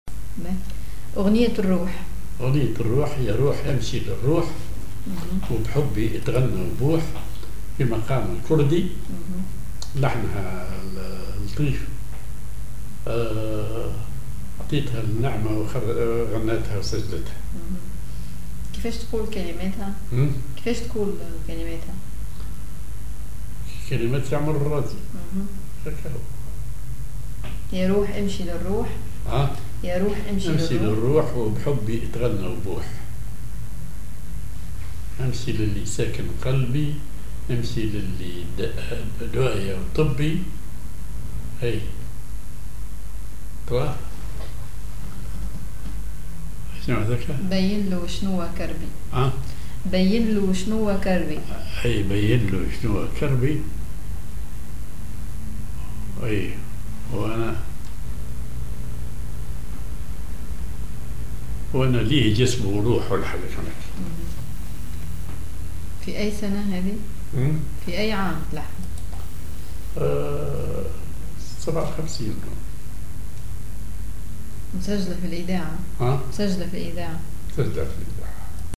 Maqam ar بياتي
genre أغنية